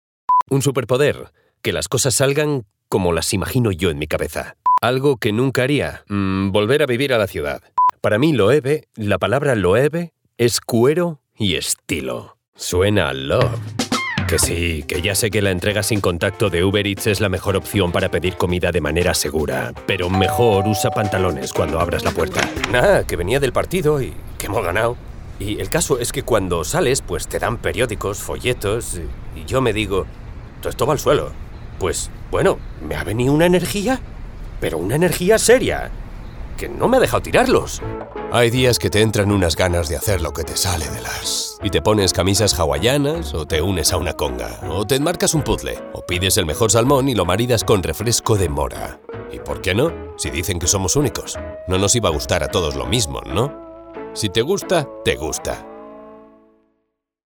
QUE NO PAREZCA LOCUTOR
CASUAL DEMO